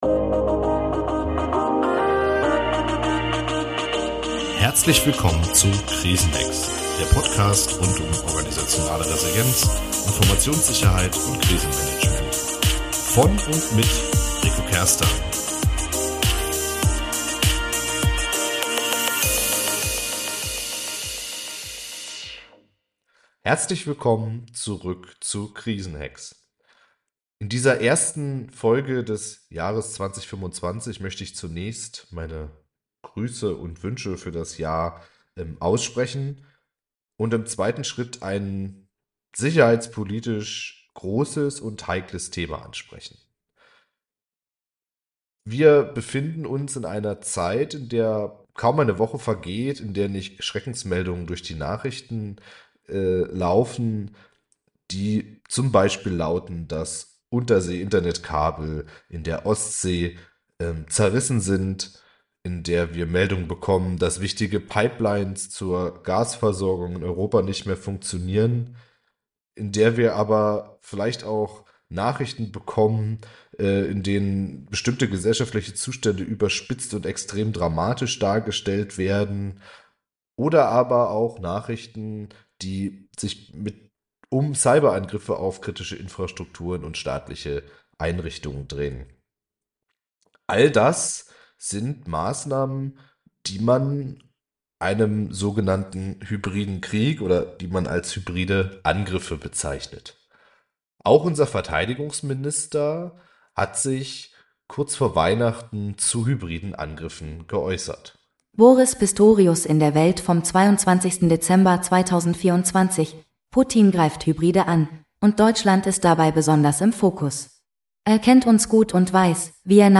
Beide Gesprächspartner kommen in dem Interview zu der Forderung, dass in diesem Krieg alle aufgefordert sind, Lösungsansätze zu finden, wie unsere Gesellschaft als Volkswirtschaft aber auch als Demokratie die Angriffe überstehen kann, die vornehmlich auch Russland und China kommen. Außerdem sprechen bei über Wargames.